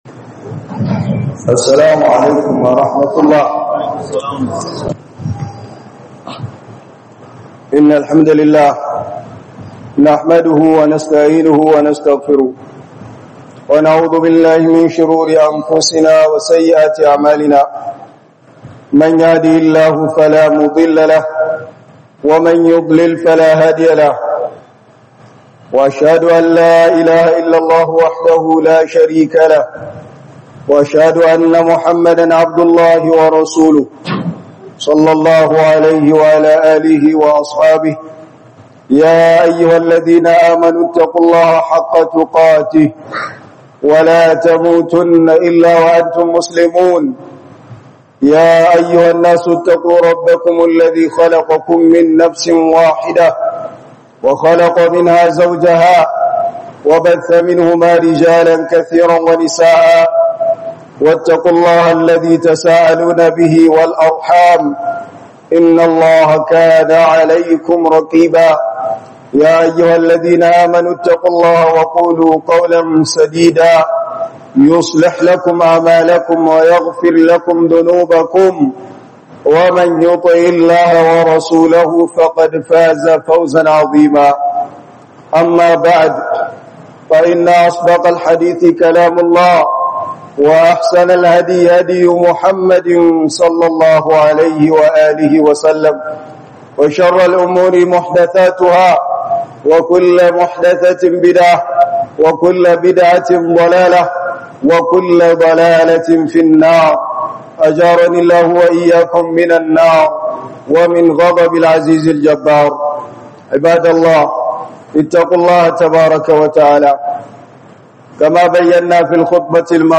hudubar juma'a 19 Septembre 2025